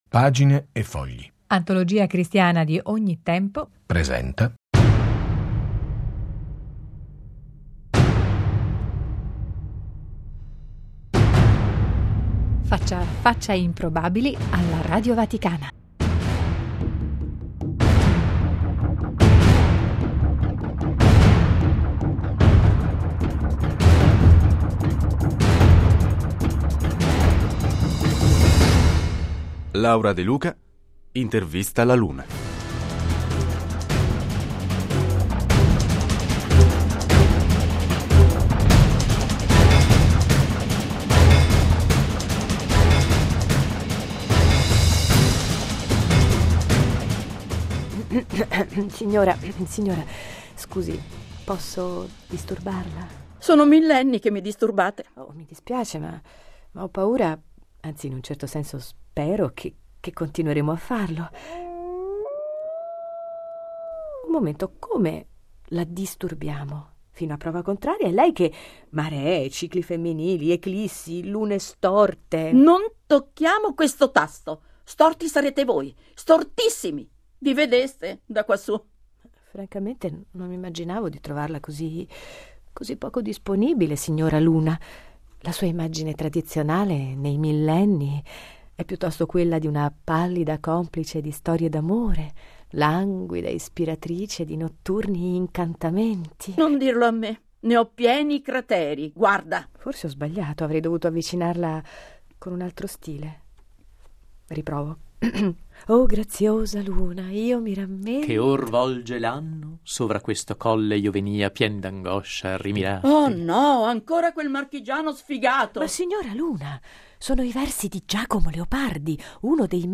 Domenica 17 luglio 2011 - Oggetto di immaginazioni e fantasie da parte di tutta l'umanità di ogni epoca e paese, la Luna si lascia intervistare alla vigilia del quarantennale dal primo allunaggio, luglio 1969 La prima messa in onda di questo testo risale infatti all'anno 2009: il vago disturbo causato da quel piede umano che osò lasciare la voluminosa imporonta su quel suolo in contaminato dà il via ad una serie di confronti fra mondo umano e mondo celeste.